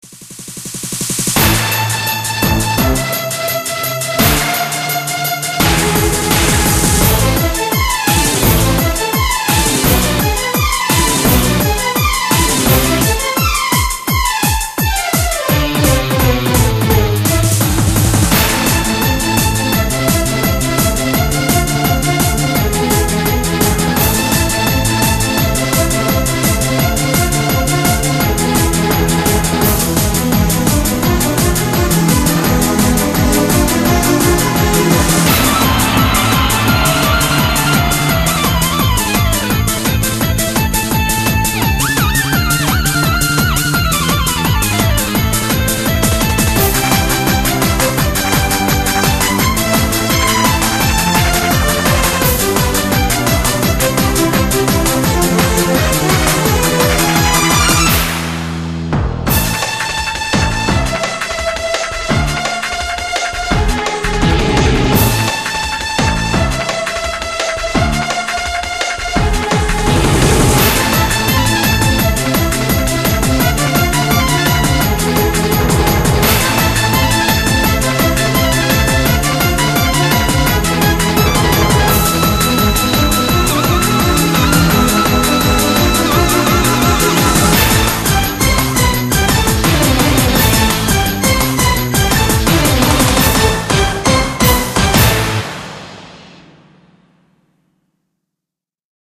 BPM169--1
Audio QualityPerfect (High Quality)